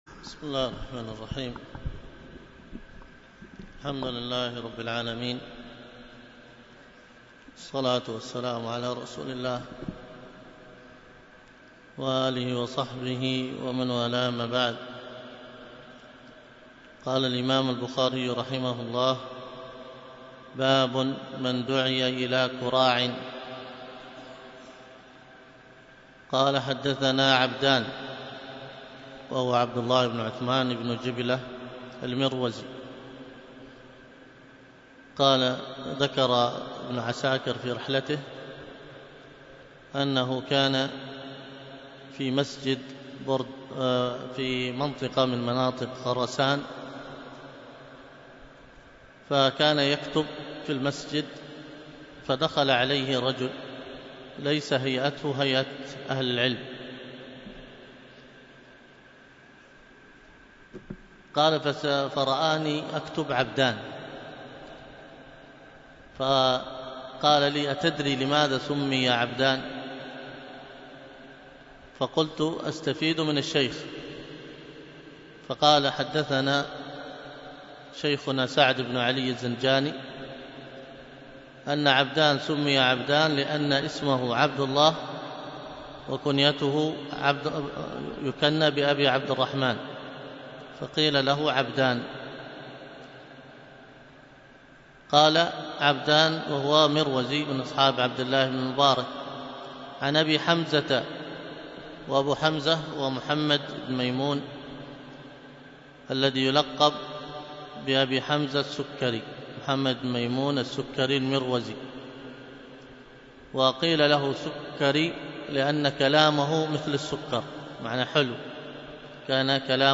الدرس